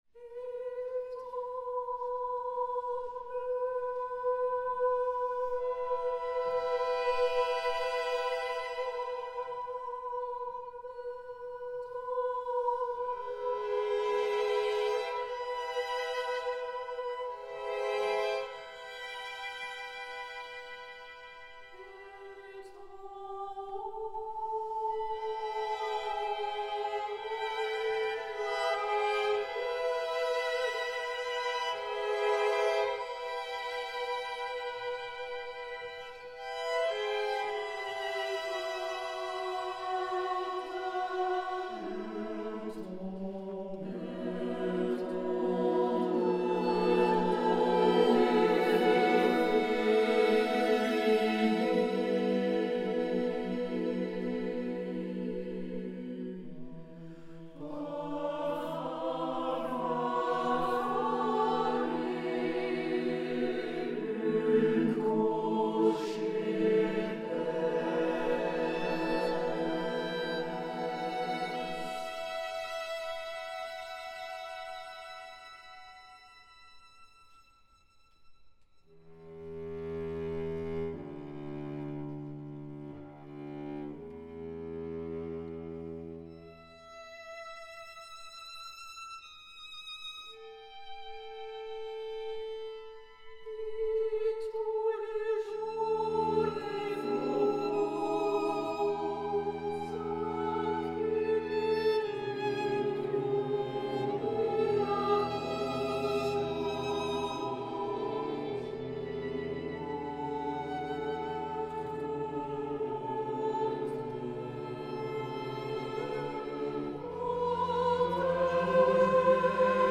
pour choeur mixte et quatuor à cordes